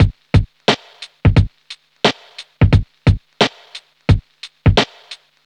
• 88 Bpm Drum Beat G Key.wav
Free breakbeat - kick tuned to the G note. Loudest frequency: 925Hz
88-bpm-drum-beat-g-key-CY0.wav